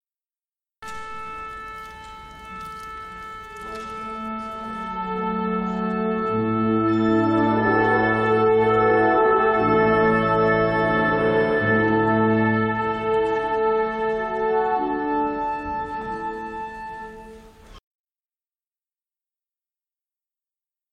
Orquesta_afinando_cortada.mp3